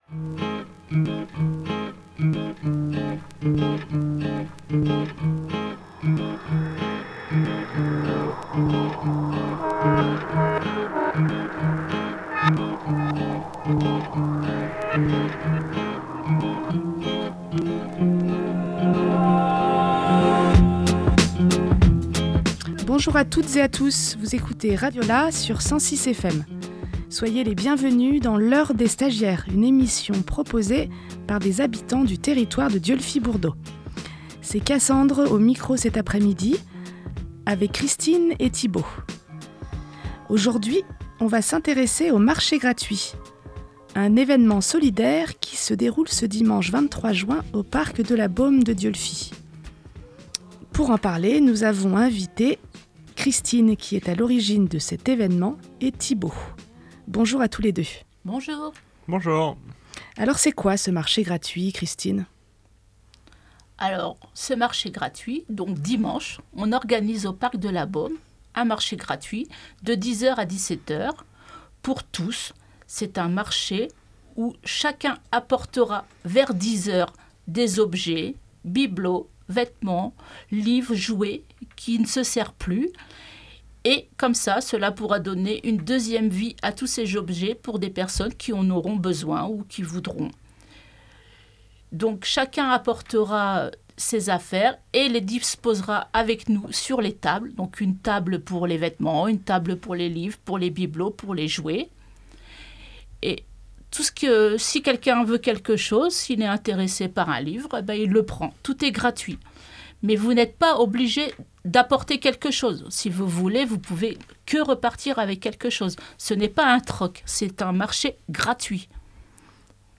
19 juin 2024 11:02 | Dessine-moi une radio, Interview